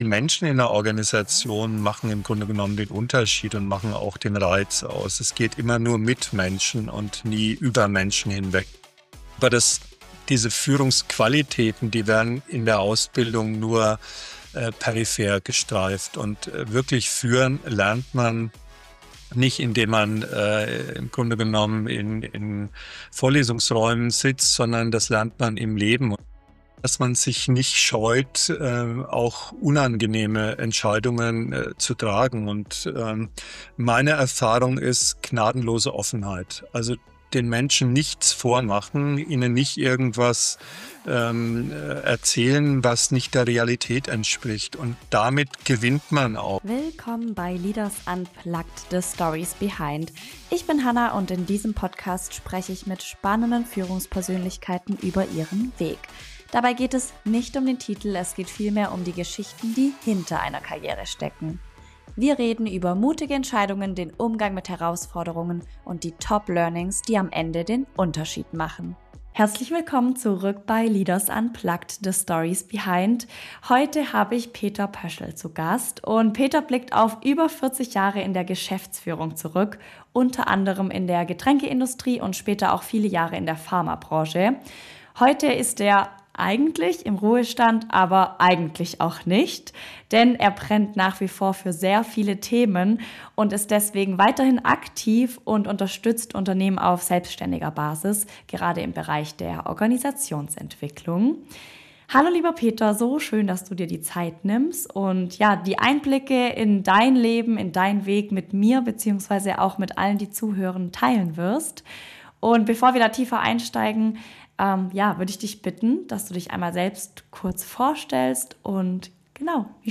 Ein Gespräch über Entscheidungen, Verantwortung, Veränderung und warum Führung immer beim Menschen beginnt. Mit ehrlichen Einblicken aus Praxis, Krisen und Transformationen.